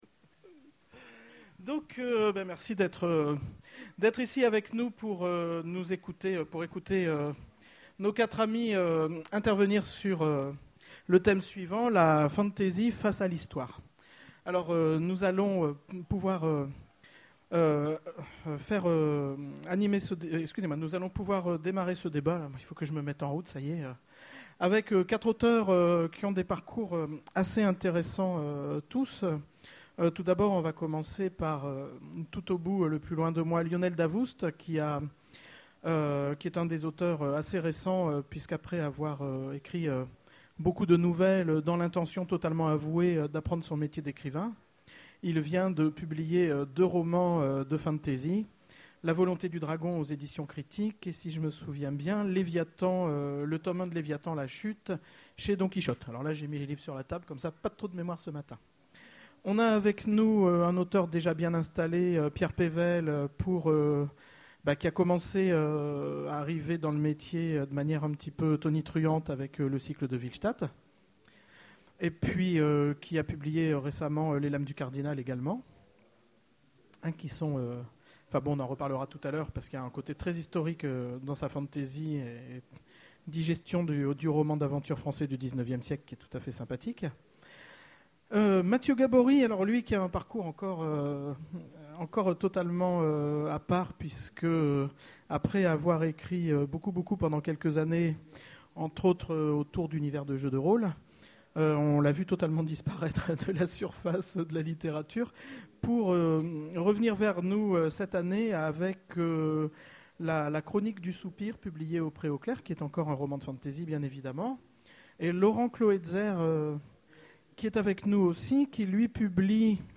Utopiales 2011 : Conférence La fantasy face à l'histoire (audio)